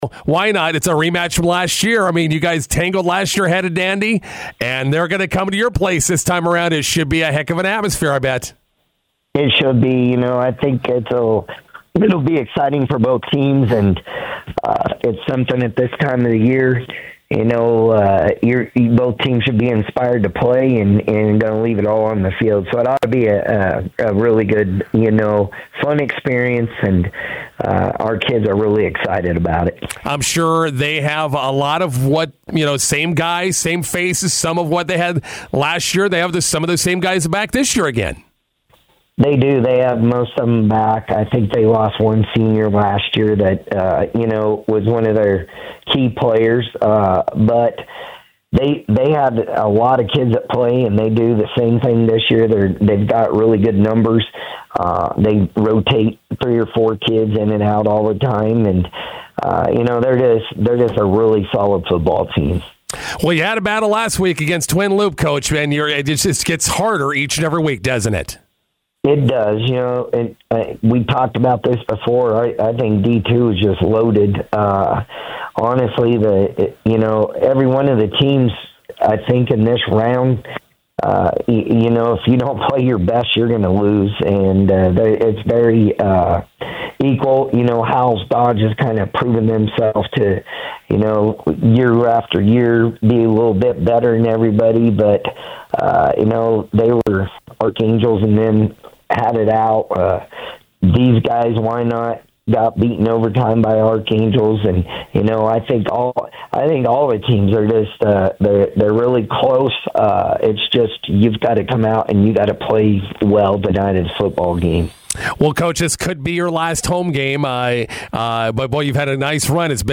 INTERVIEW: Hitchcock County and Wynot hook up in D2 quarterfinals, rematch from 2024 playoffs.